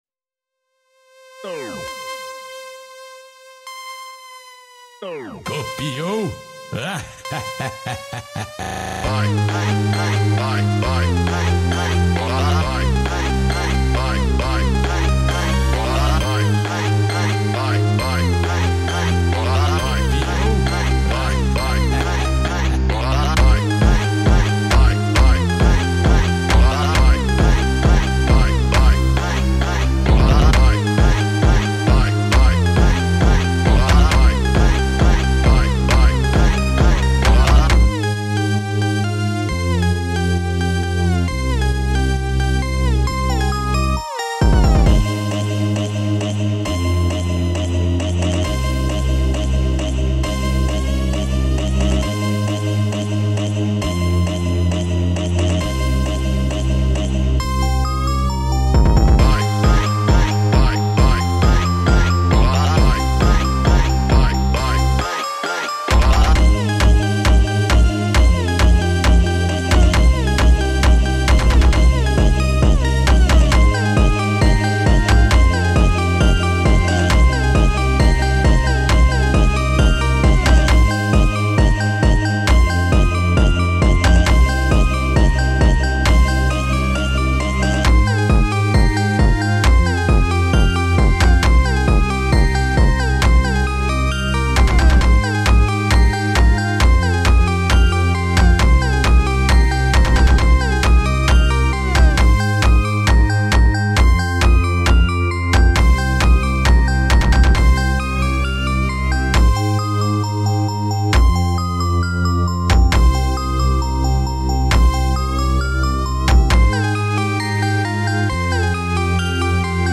сочетая элементы электронного жанра с мелодичным вокалом.